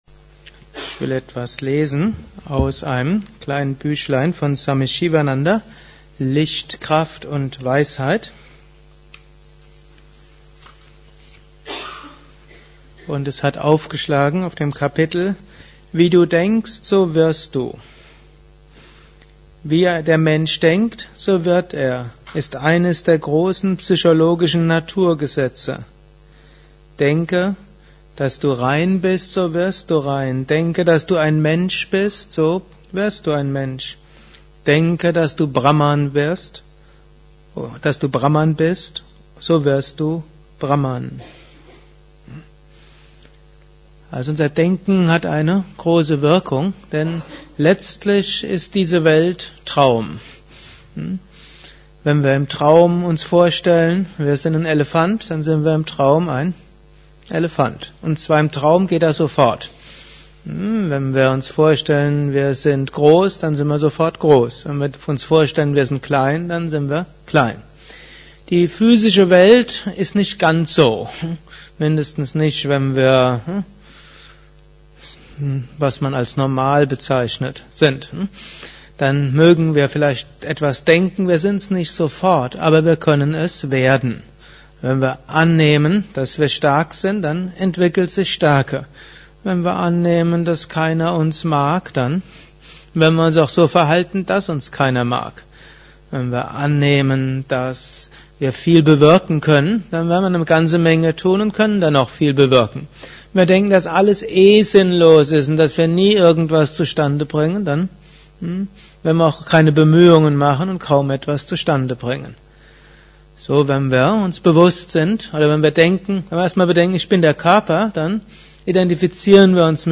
Kurzvorträge
Aufnahme während eines Satsangs gehalten nach einer Meditation im
Yoga Vidya Ashram Bad Meinberg.